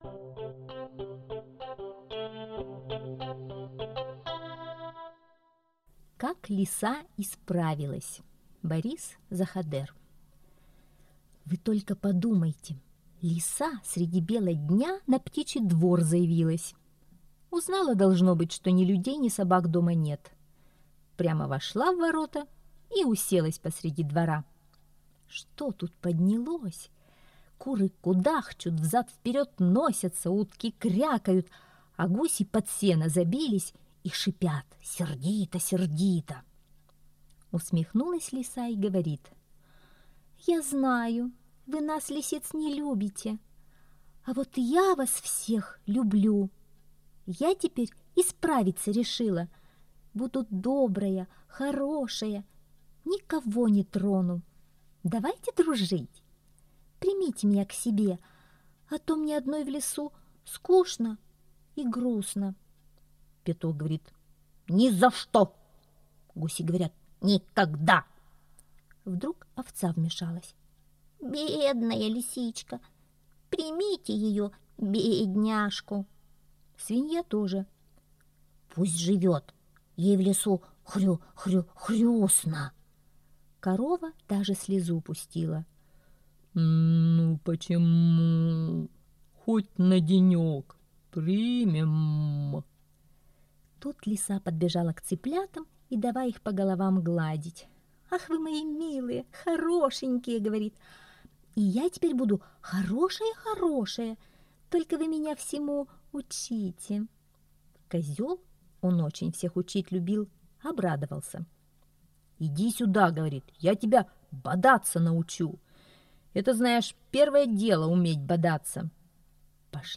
Как лиса исправилась - аудиосказка Заходера Б. Сказка про Лису, которая зашла на птичий двор и убедила всех ее жителей, что она исправилась.